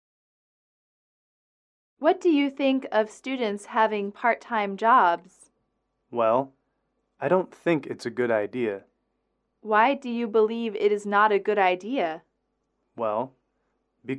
英语口语情景短对话22-4：对学生打工的看法(MP3)